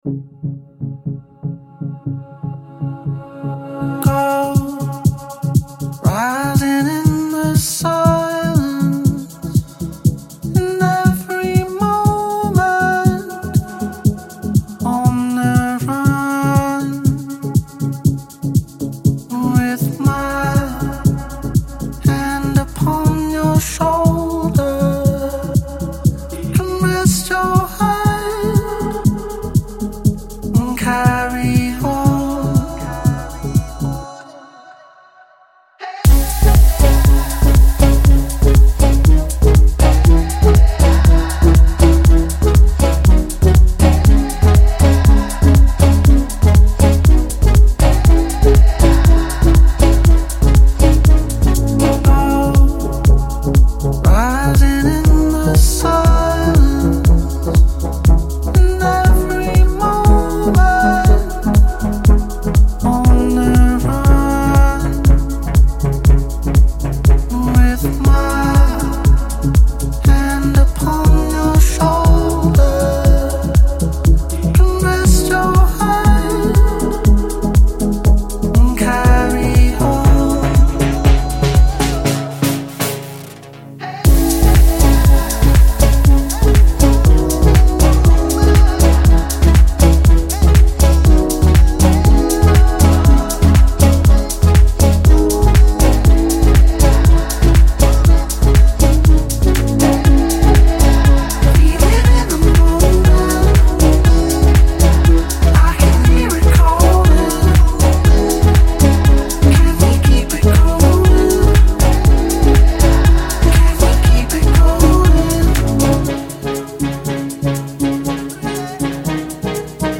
Зарубежная музыка, Клубная музыка, Deep House и Afro House